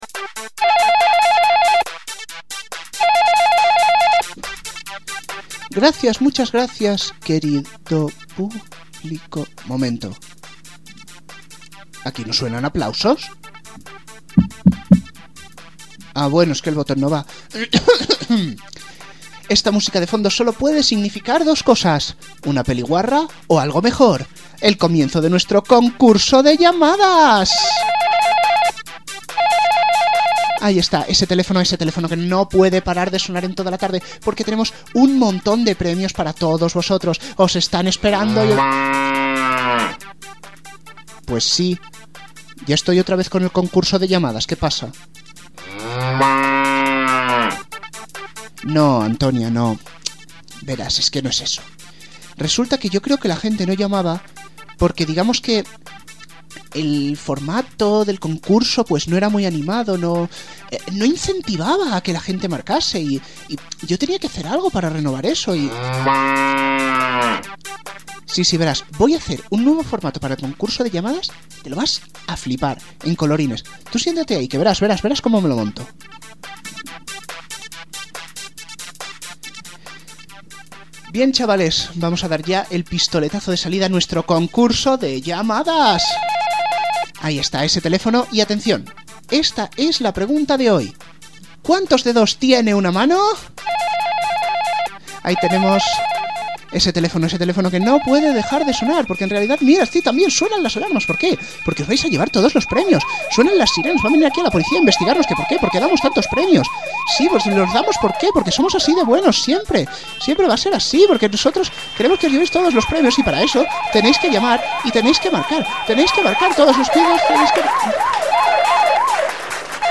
Los efectos sonoros los carga el diablo: